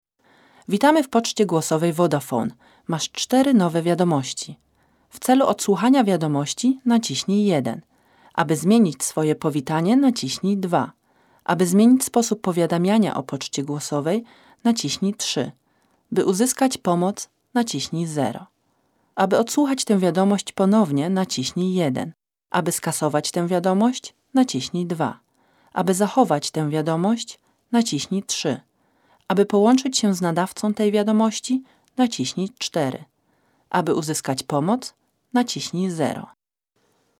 Sprecherin polnisch.
Kein Dialekt
Sprechprobe: Industrie (Muttersprache):
polish female voice over talent